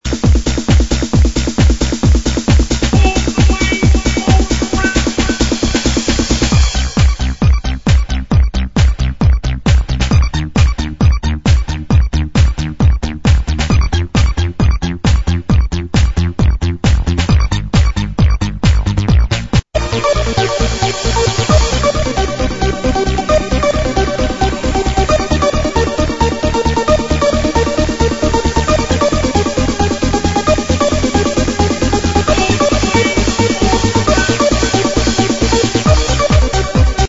the sample is like 2 seconds long! and its only a drum beat...no way you can ID this one...give a better sample.